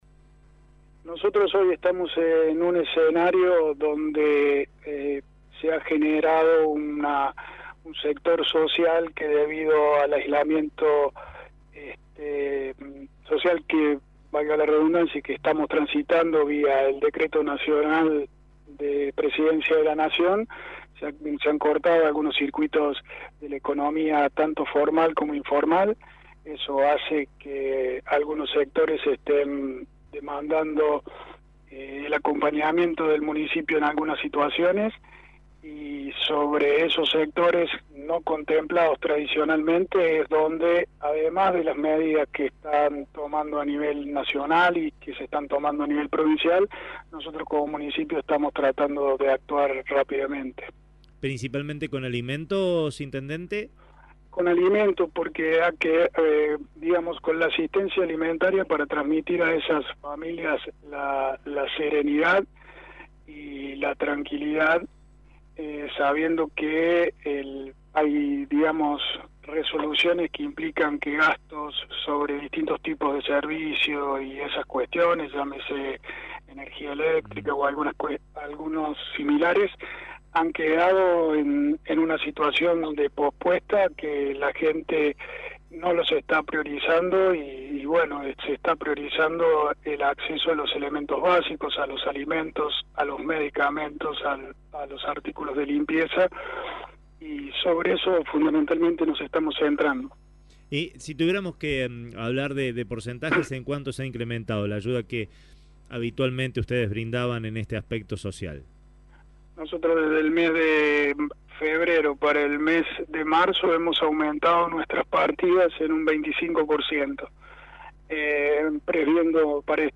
El intendente interino Pablo Rosso fue consultado sobre el tema.